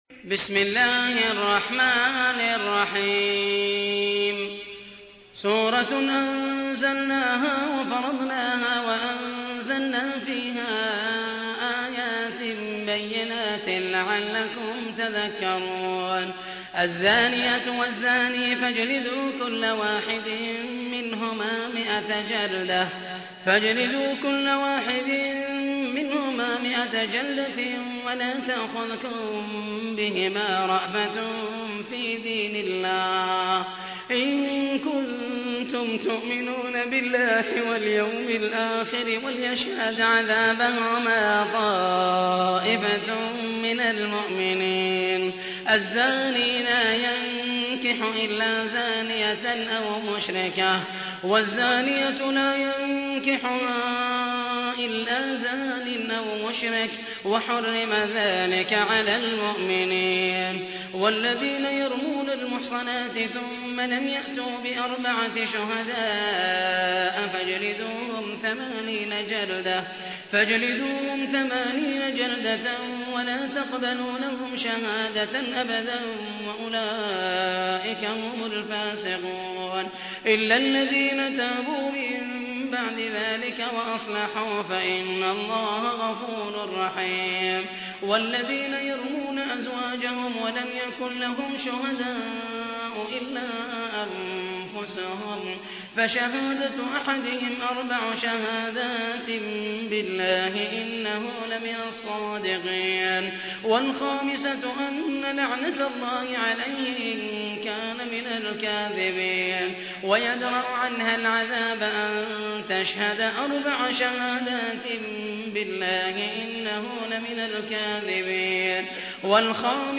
تحميل : 24. سورة النور / القارئ محمد المحيسني / القرآن الكريم / موقع يا حسين